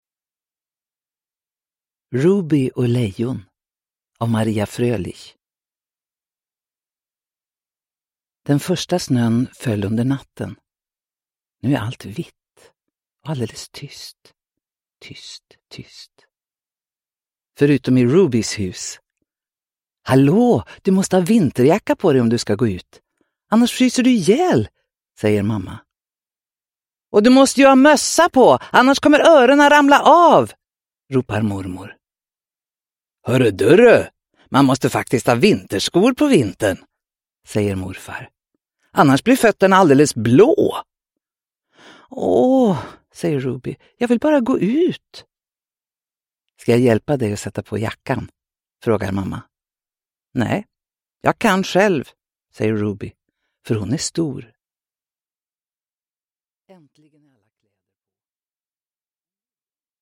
Uppläsare: Astrid Assefa